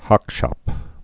(hŏkshŏp)